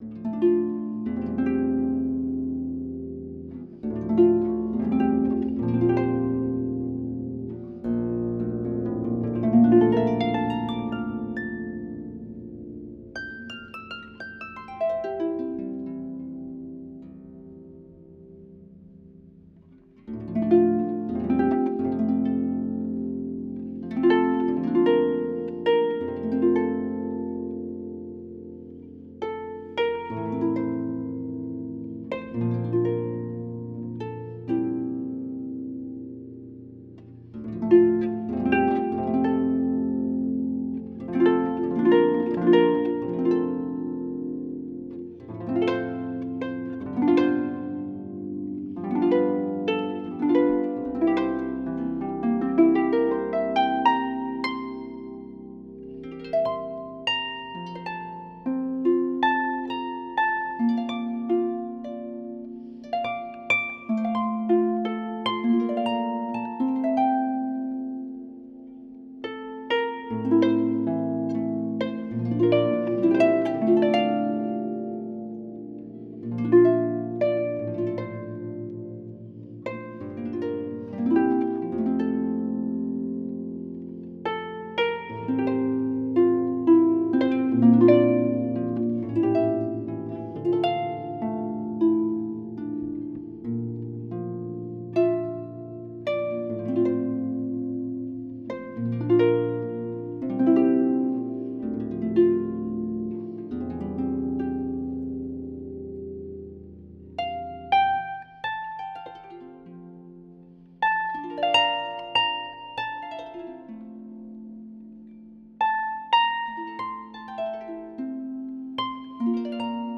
hymn